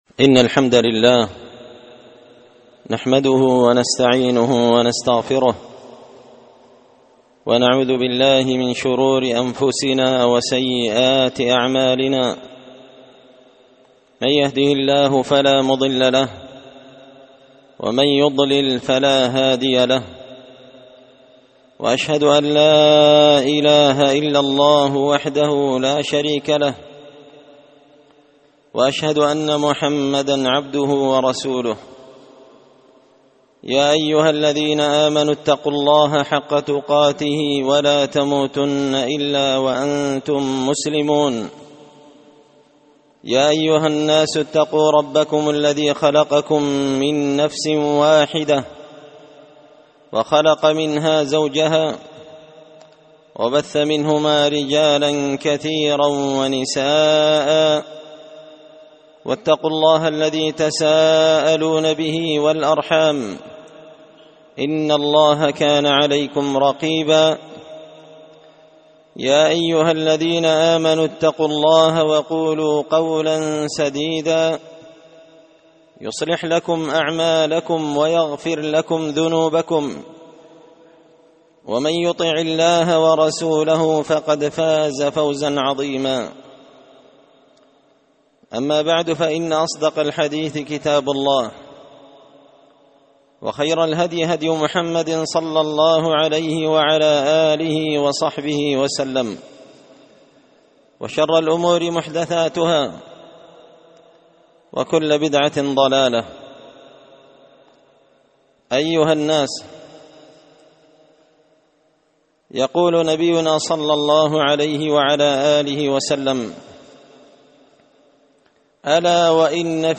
خطبة جمعة بعنوان – الغفلة
دار الحديث بمسجد الفرقان ـ قشن ـ المهرة ـ اليمن